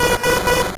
Cri de Démanta dans Pokémon Or et Argent.